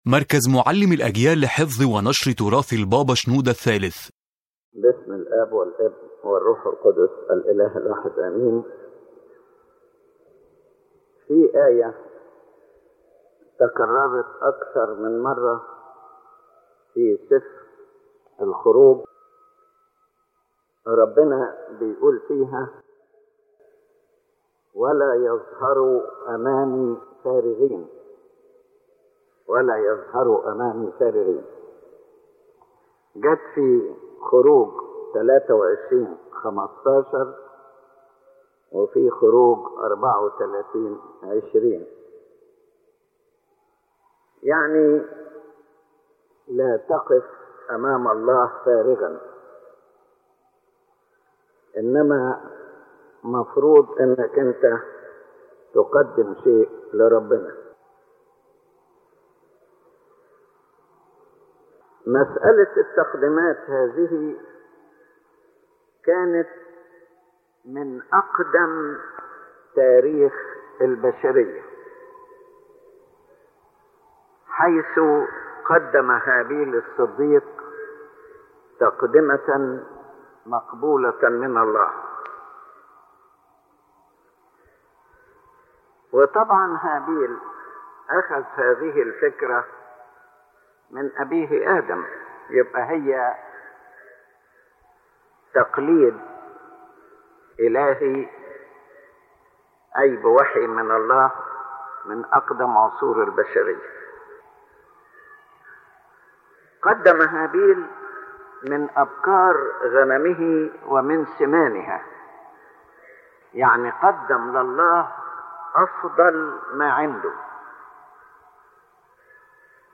⬇ تحميل المحاضرة الفكرة الأساسية للمحاضرة تدور المحاضرة حول وصية إلهية واضحة: “ لا تظهروا أمامي فارغين “ كما وردت في سفر سفر الخروج .